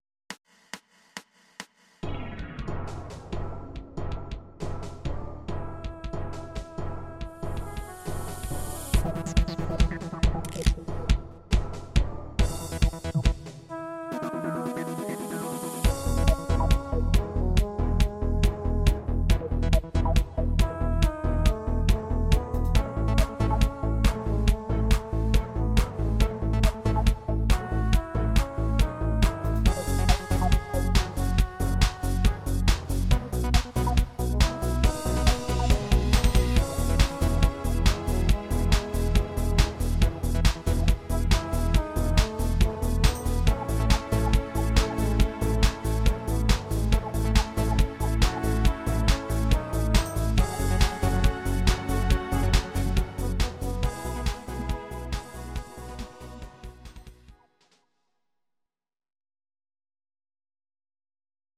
These are MP3 versions of our MIDI file catalogue.
Your-Mix: Disco (724)